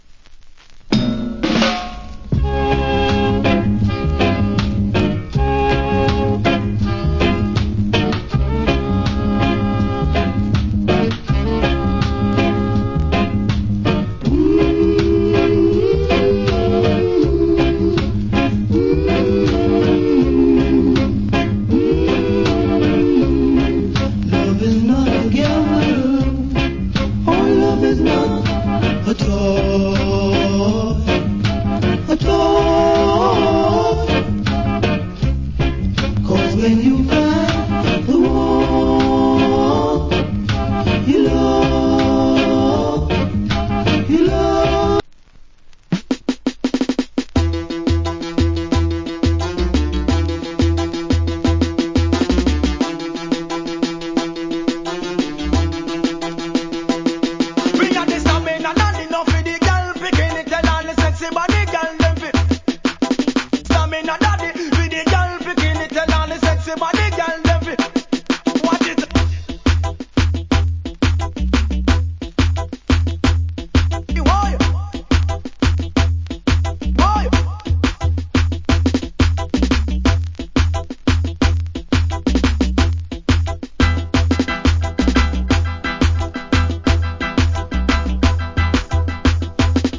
Foundation Rock Steady.